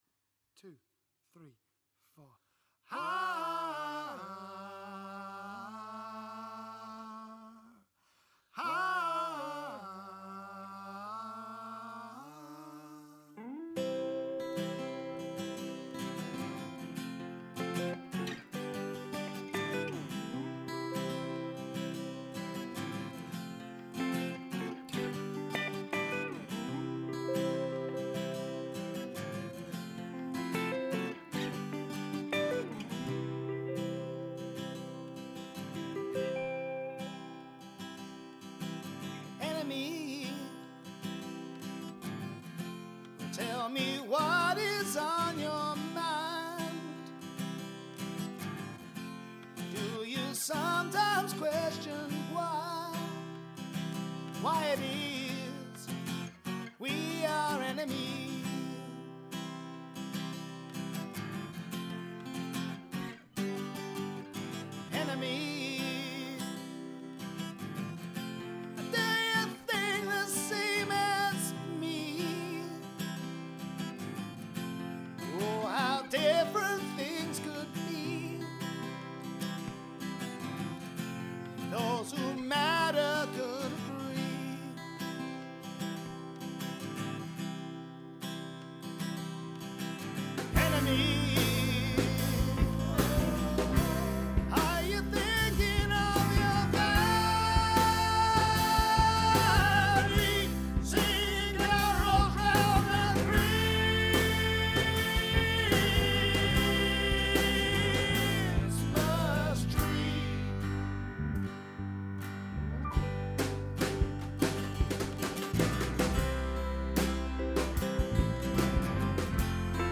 Rock track - nearly there but something isnt sitting right.
Seems like something isnt sitting right balance wise but I've lost perspective a bit so I'm taking a break and putting it out to consultation. Vocals aren't final.